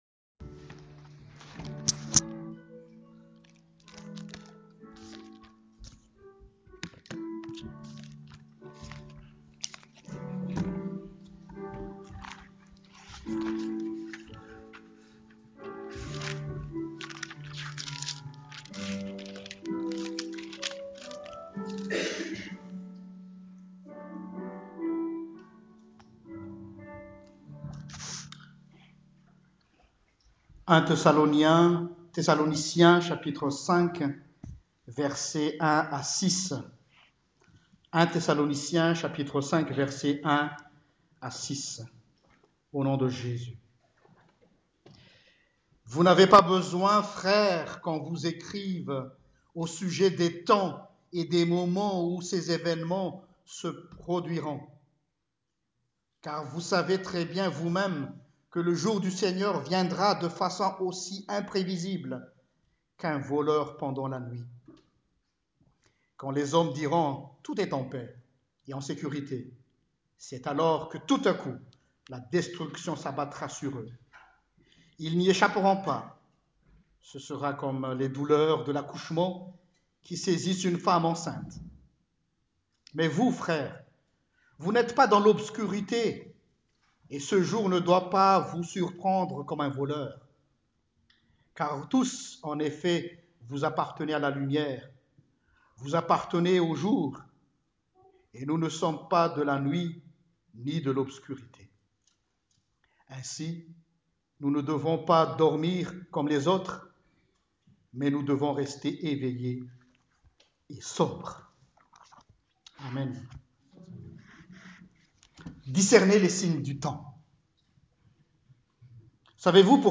PREDICATION DU 19 NOVEMBRE
predication-19-Nov.m4a